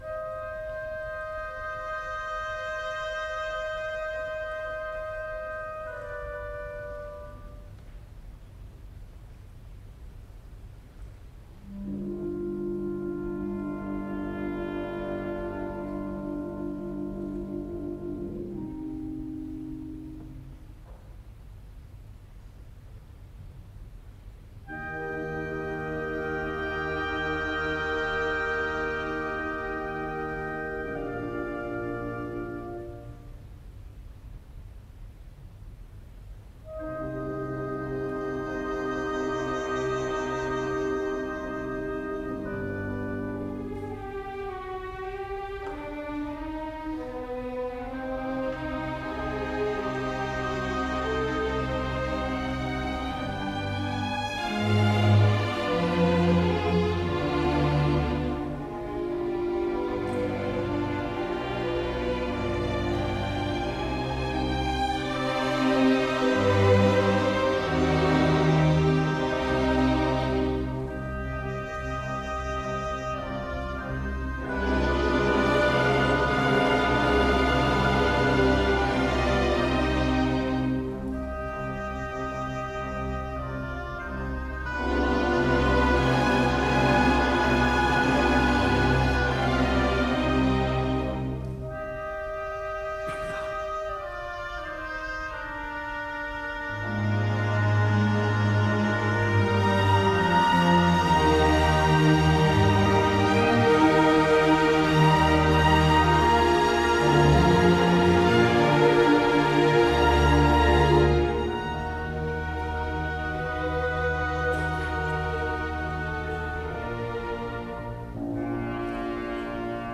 Bien que brève, cette Maurerische Trauermusik (« Musique funèbre » ou « Ode funèbre ») en ut mineur K 477 revêt une importance toute spéciale dans la production mozartienne, ne serait-ce que pour une raison : parmi la douzaine d’œuvres à destination maçonnique que le musicien a écrites entre 1785 et 1791, elle est la seule à ne pas faire appel à la voix, et peut-être la seule à s’élever bien au dessus des simples pièces de circonstance.
Destinée au rituel de la Maîtrise, elle fait appel, à côté des cordes, à un riche ensemble d’instruments à vent, caractéristique-clé de la musique maçonnique de Mozart.
Tout cela jusqu’à une conclusion fascinante entre toutes, ce passage en majeur sur le dernier accord qui, après les sanglots et la douleur, apporte une ouverture magique sur la Lumière.
Wolfgang Amadeus MozardMaurerische Trauermusik, K 477, par le New Philharmonia Orchestra sous la direction de Rafael Kubelik, enregisrement public de 1974